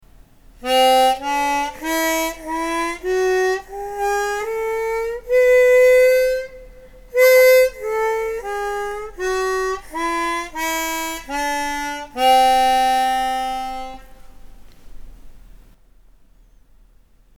低音の吸い音が出なかったり、音が詰まったりしているように感じます。